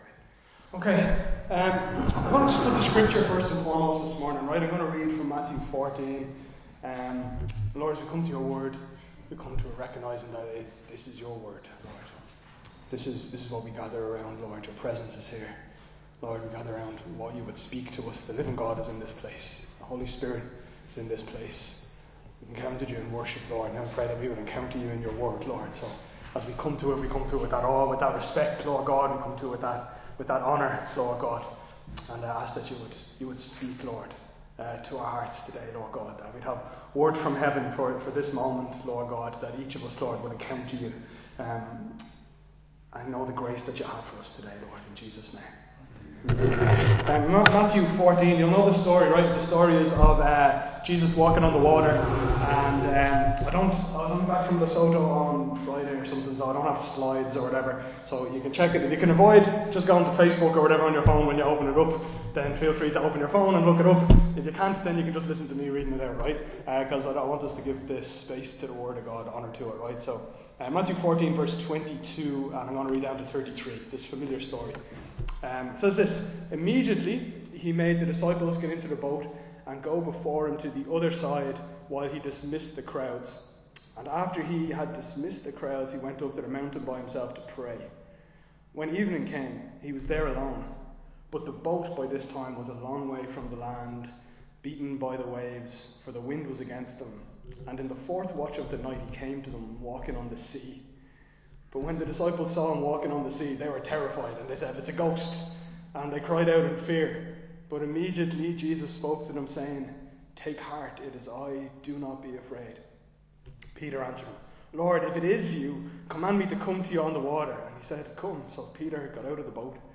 Recorded live in Liberty Church on 9 March 2025